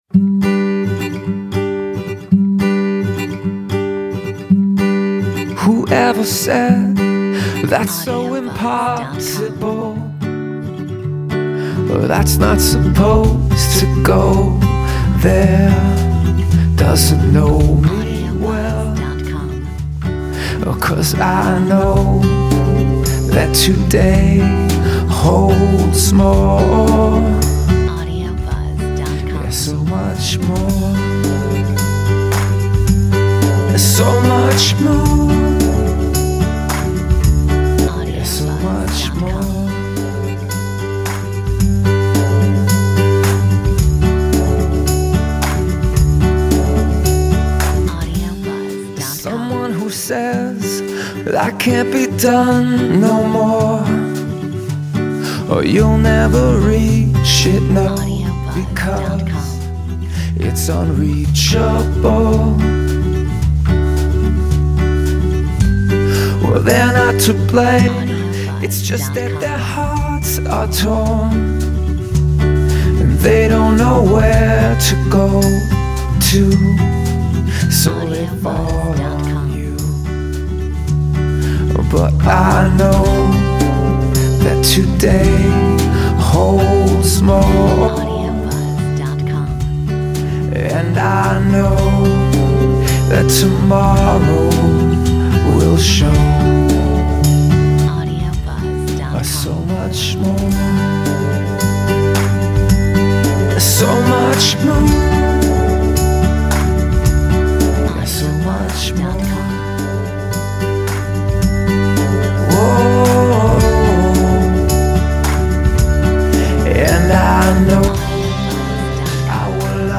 Metronome 110